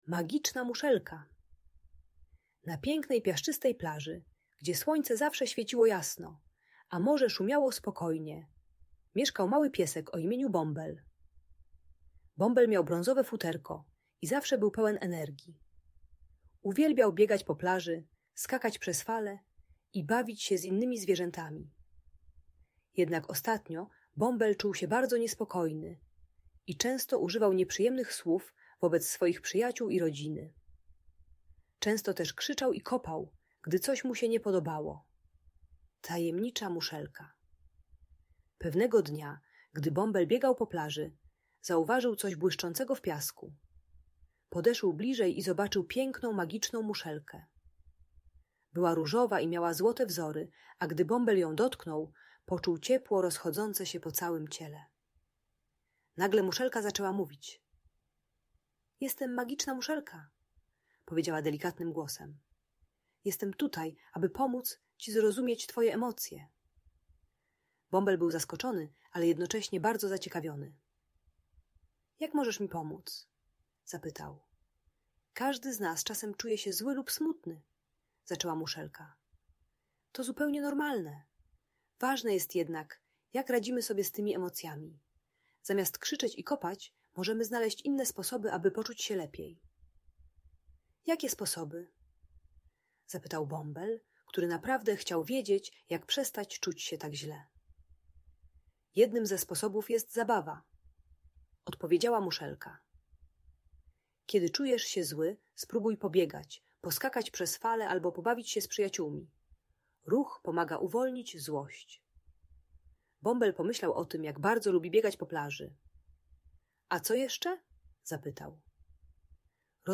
Magiczna Muszelka - Bunt i wybuchy złości | Audiobajka